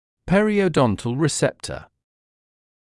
[ˌperɪəu’dɔntl rɪ’septə][ˌпэриоу’донтл ри’сэптэ]периодонтальный рецептор; рецептор, расположенный в тканях пародонта